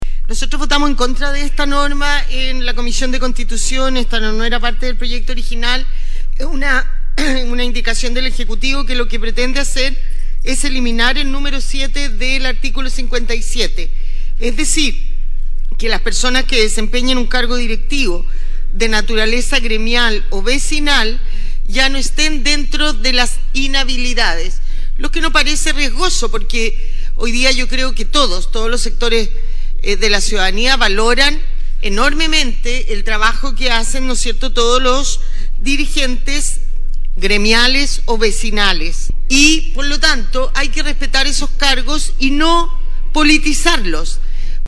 Luego, la Sala rechazó con 16 votos a favor, 24  en contra y dos abstenciones, la norma que buscaba terminar con la inhabilidad para ser candidatos al Parlamento, de las y los dirigentes vecinales y gremiales, por lo que se mantiene esa inhabilidad. En este punto hicieron uso de la palabra las senadoras Luz Ebensperger y Claudia Pascual.